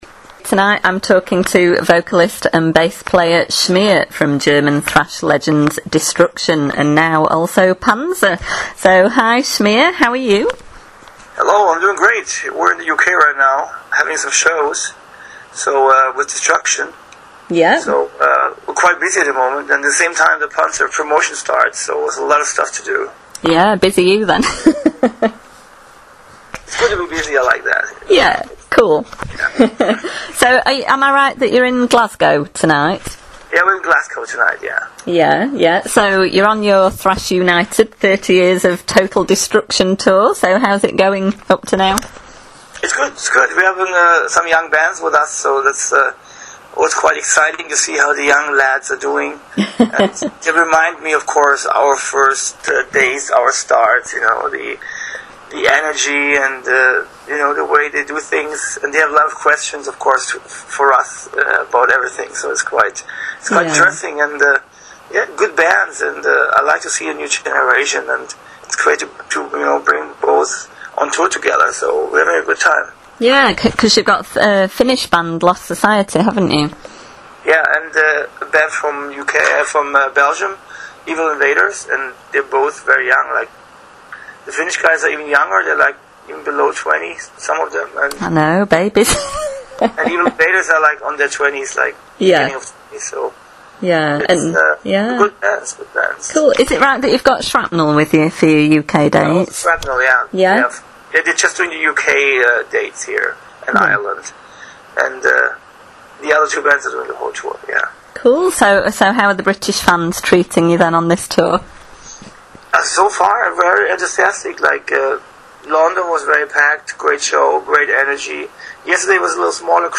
Recently Radioactive Metal conducted an interview with DESTRUCTION bassist/vocalist SCHMIER:
interview-schmier-panzer-2014.mp3